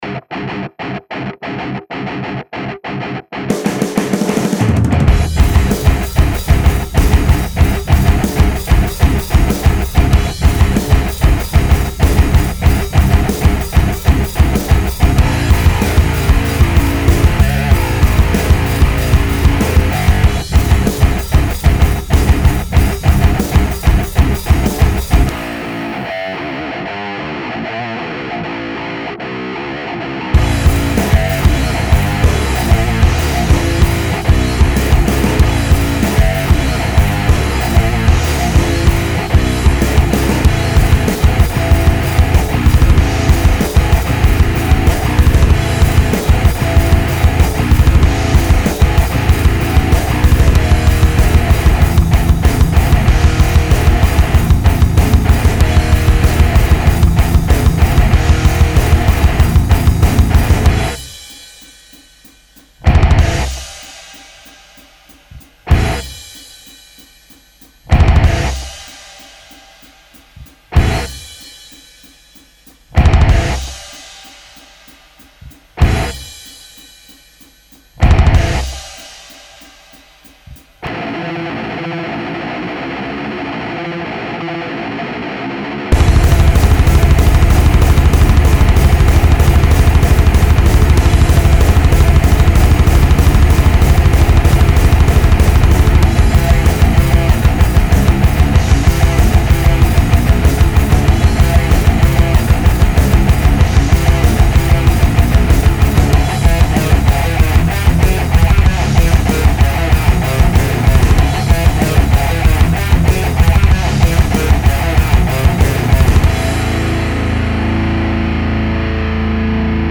J'ai fait aussi un petit essai en higain, pareil avec un préset très simple :
Je trouve qu'on est carrément dans l'esprit de la série XT sur les higain.